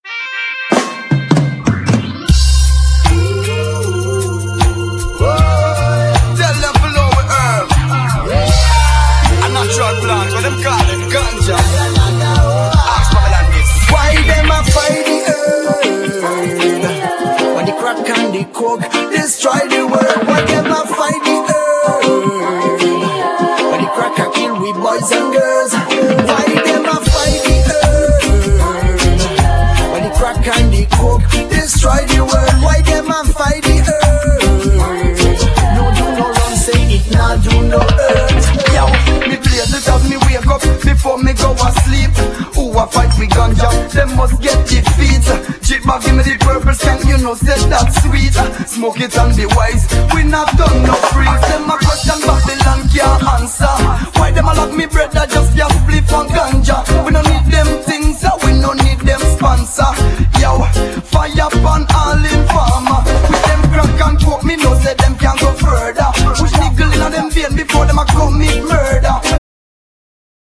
Genere : Raggae